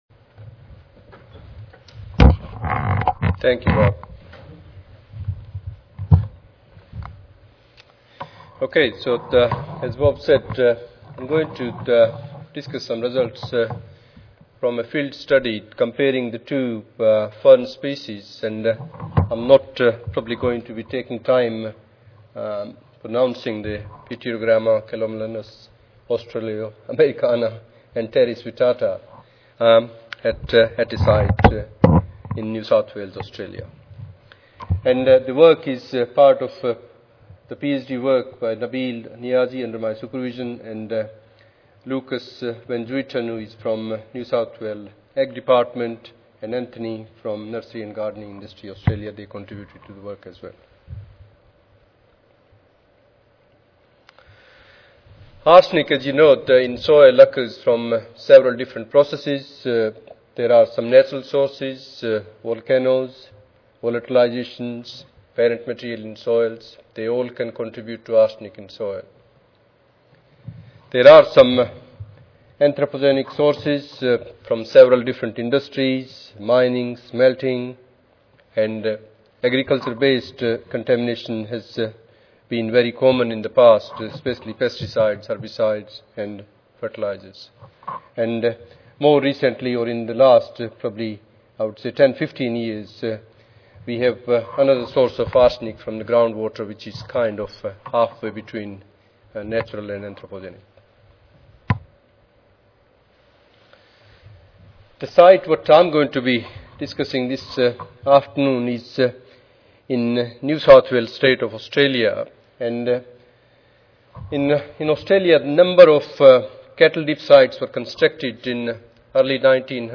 Abstract: Phytoremediation Potential of Pityrogramma Calomelanos Var. Austroamericana and Pteris Vittata L. At a Highly Variable Arsenic Contaminated Site. (ASA, CSSA and SSSA Annual Meetings (San Antonio, TX - Oct. 16-19, 2011))